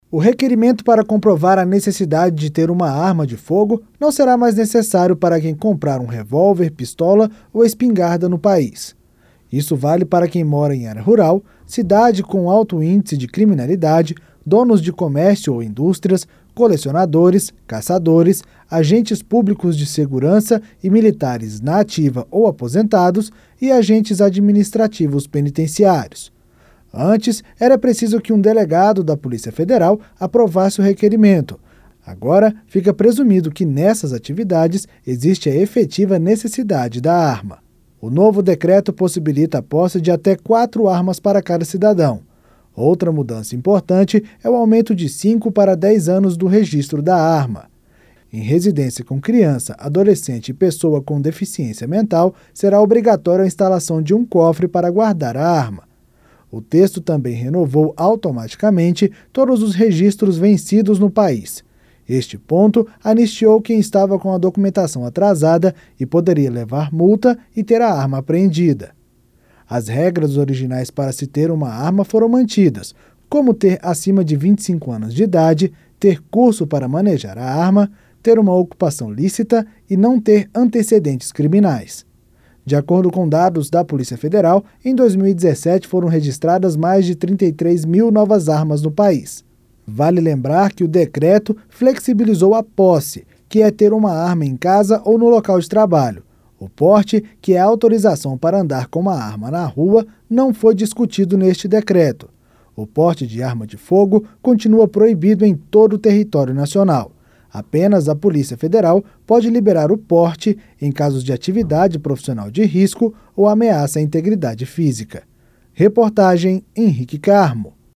A posse de pistolas e espingardas foi facilitada, mas as normas para o porte continuam as mesmas. Entenda o que mudou com o repórter